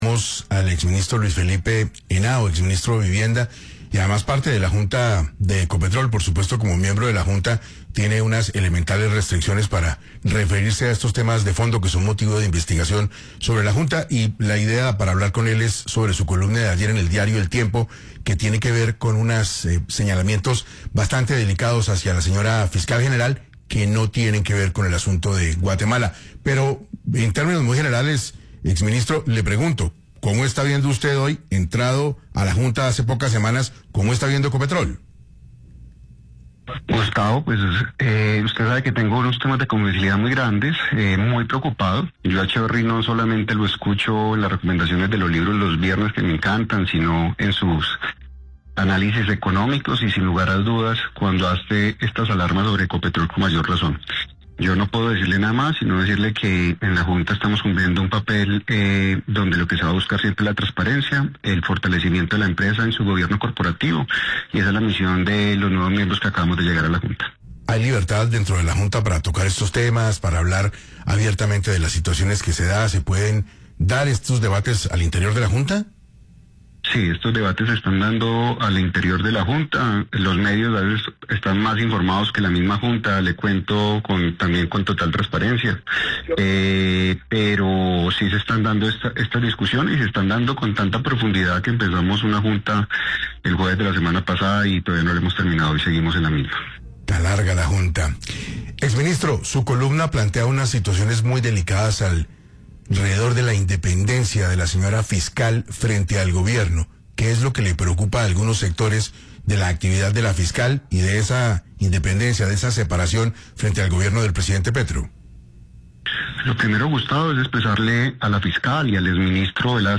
En 6AM de Caracol Radio el exministro de vivienda y actual miembro de la junta directiva de Ecopetrol, Luis Felipe Henao, criticó la gestión de la actual Fiscal, Luis Adriana Camargo, cuestionando su independencia del gobierno.